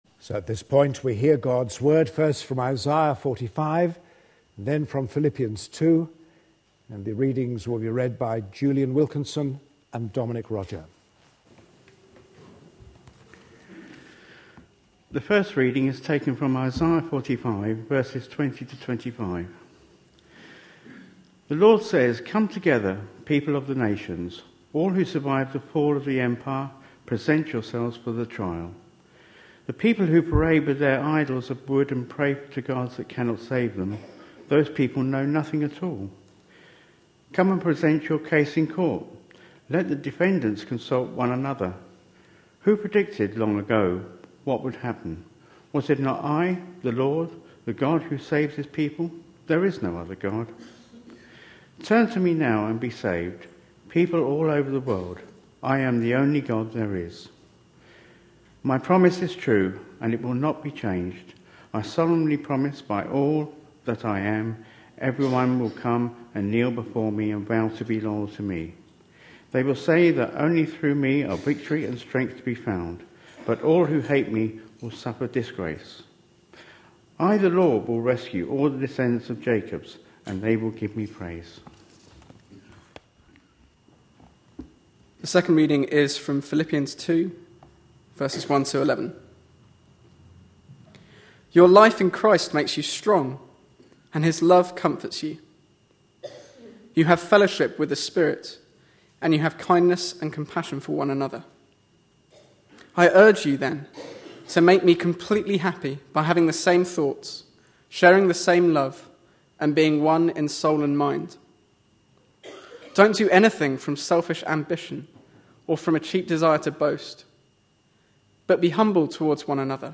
A sermon preached on 11th December, 2011.